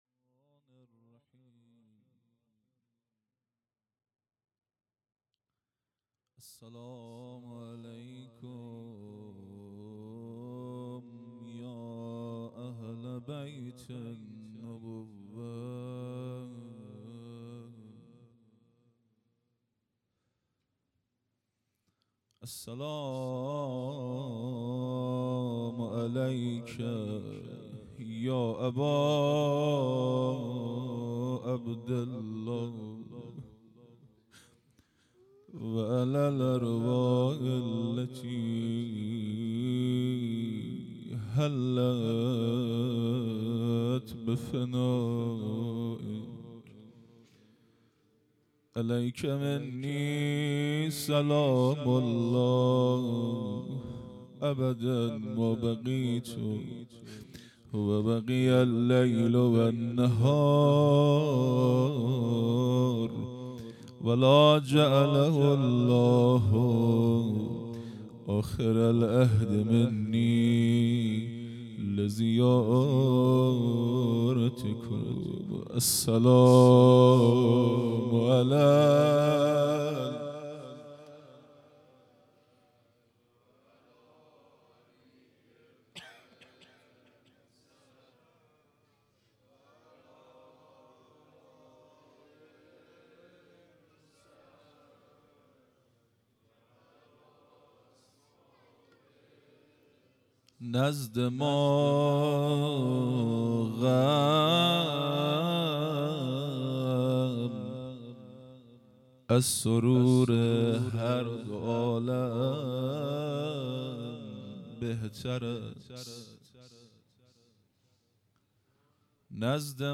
روضه|حضرت رقیه
هیئت مکتب الزهرا(س)دارالعباده یزد
محرم ۱۴۴۵ _ شب سوم